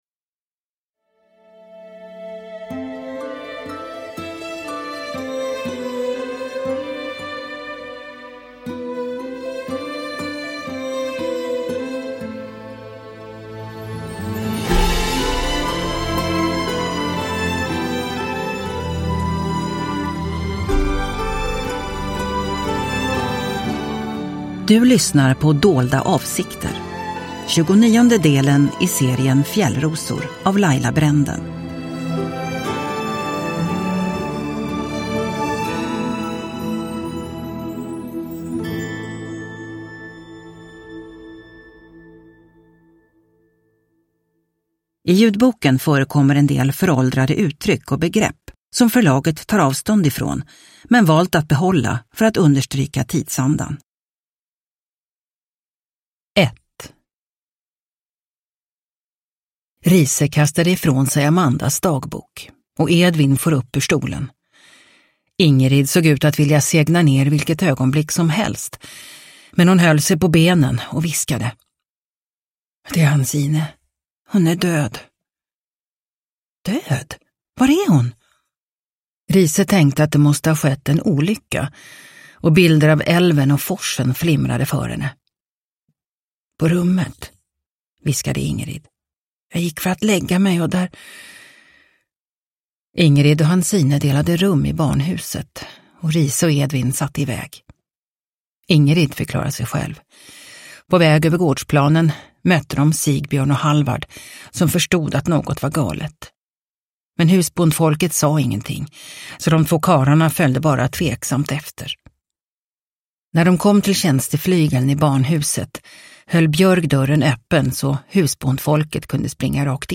Dolda avsikter – Ljudbok – Laddas ner